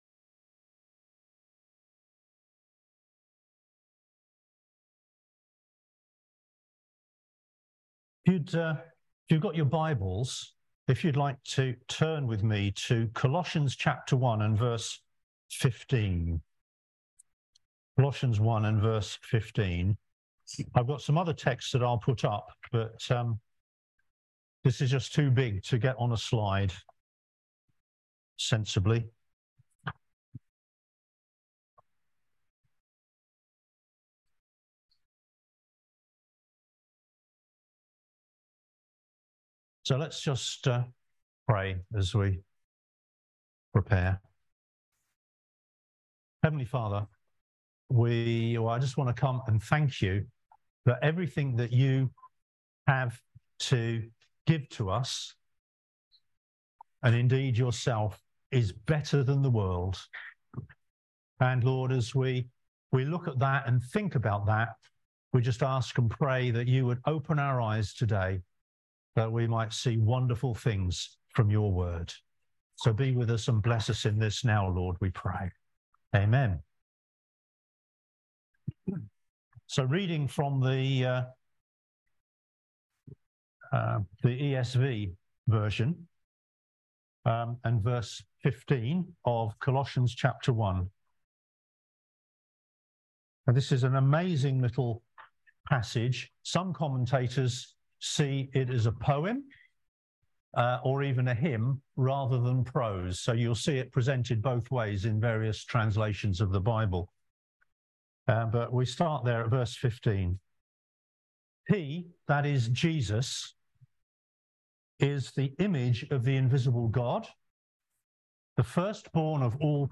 Service Type: Lighthouse Breakfast